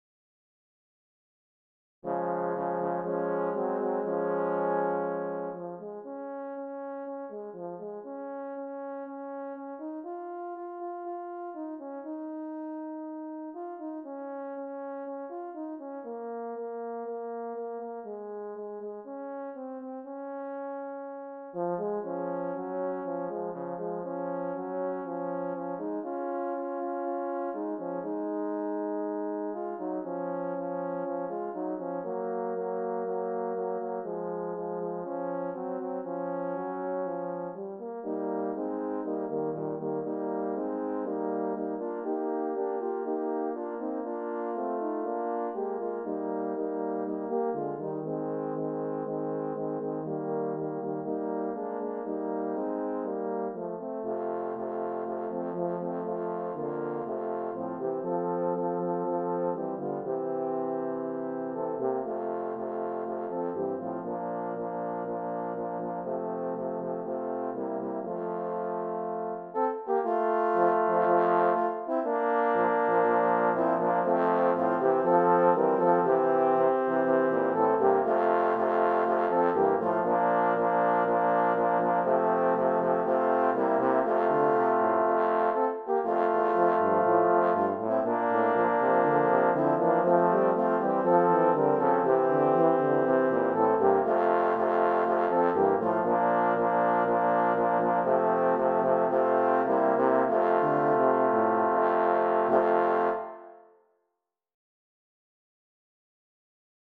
Here is a great old hymn by Hoffman that I have arranged for horn quartet. this is 12 out of 50.
HYMN MUSIC